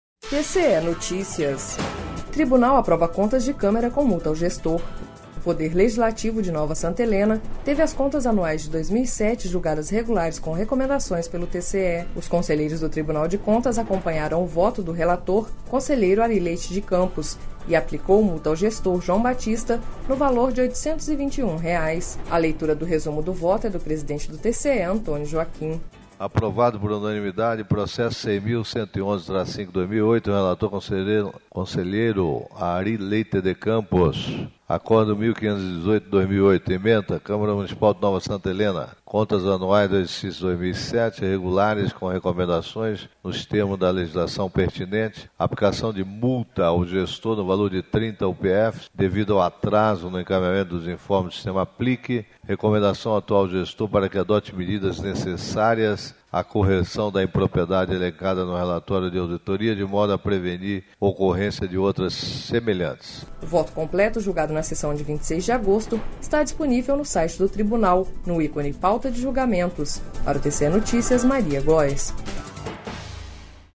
Sonora: Antonio Joaquim conselheiro presidente do TCE-MT